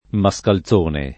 [ ma S kal Z1 ne ]